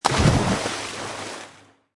Download Splash sound effect for free.
Splash